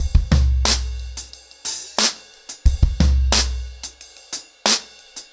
ROOTS-90BPM.19.wav